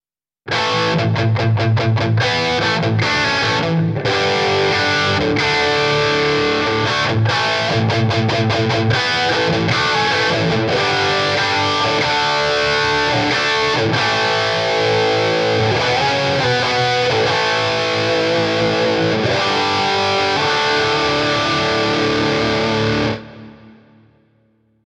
This Amp Clone rig pack is made from a Mesa Boogie JP2C, Mark IV, Mark V, Mark VII and a Mesa Triaxis preamp head.
RAW AUDIO CLIPS ONLY, NO POST-PROCESSING EFFECTS